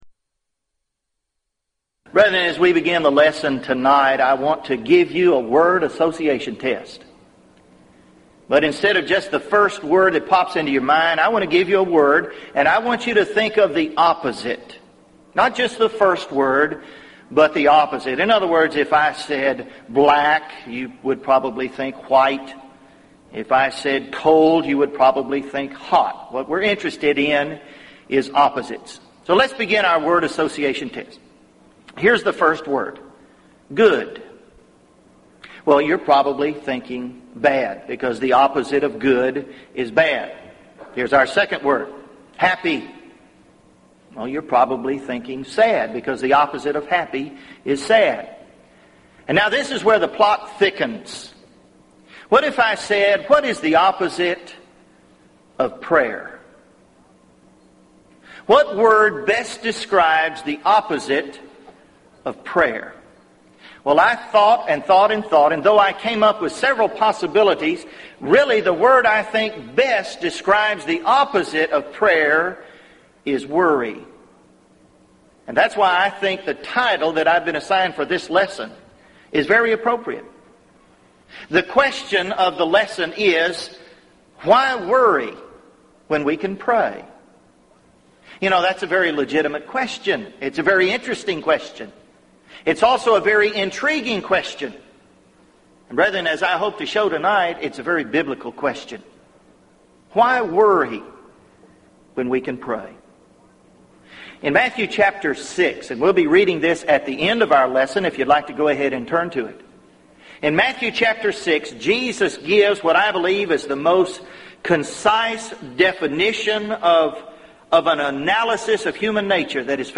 Event: 1998 Gulf Coast Lectures
lecture